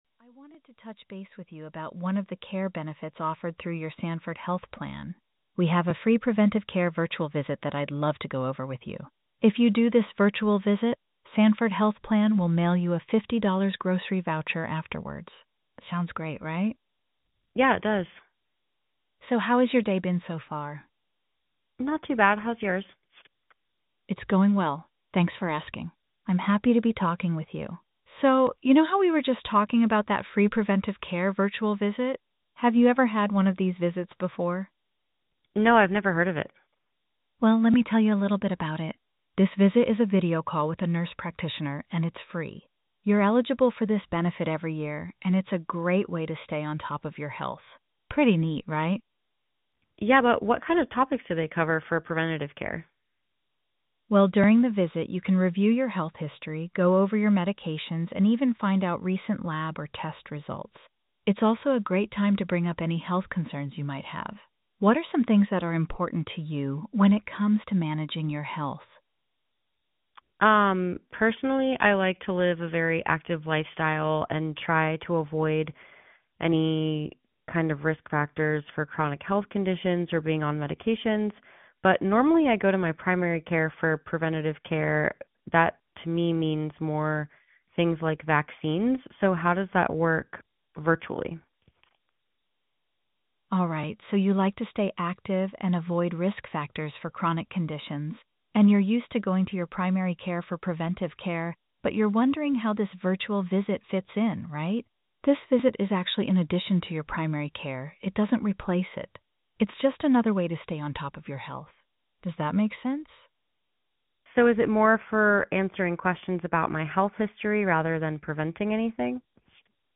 New! Introducing Jane, Your Personal AI Health Navigator
Jane will be the friendly voice calling members like you to help schedule a free preventive care visit.
shp_jane-demo_hippocratic-ai.mp3